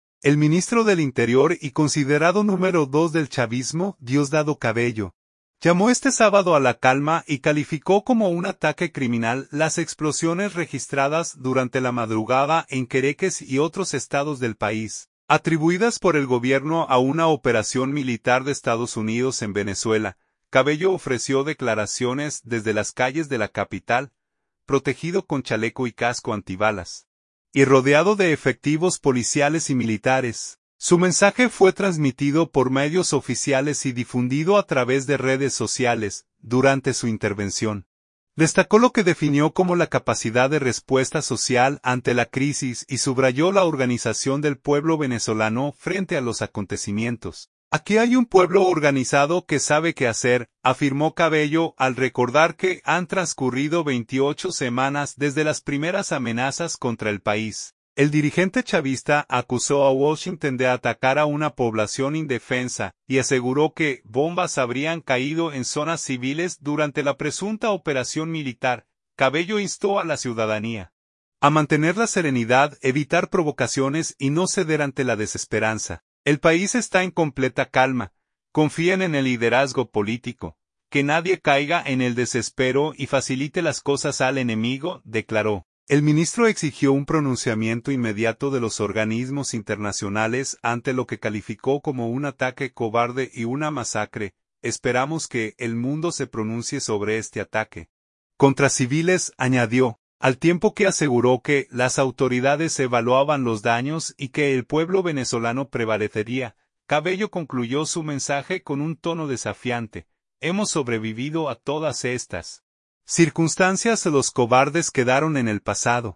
Cabello ofreció declaraciones desde las calles de la capital, protegido con chaleco y casco antibalas, y rodeado de efectivos policiales y militares.
Cabello concluyó su mensaje con un tono desafiante: «Hemos sobrevivido a todas estas circunstancias. Los cobardes quedaron en el pasado».